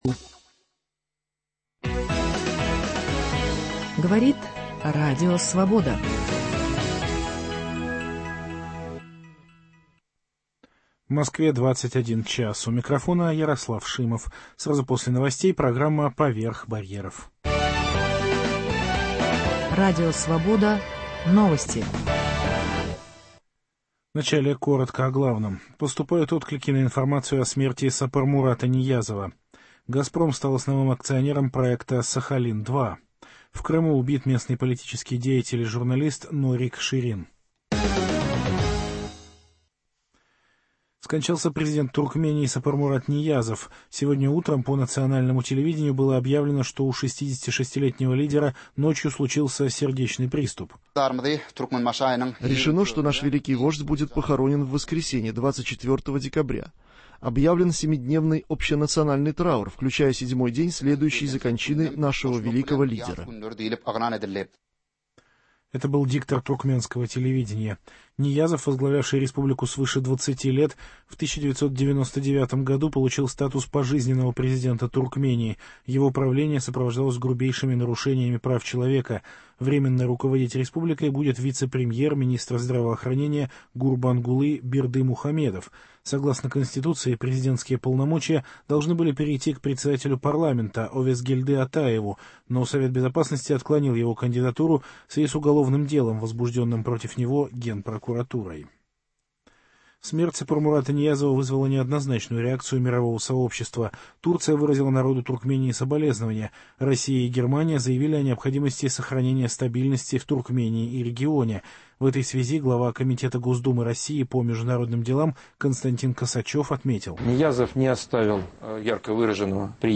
Разговор с его директором, актрисой Ольгой Кабо.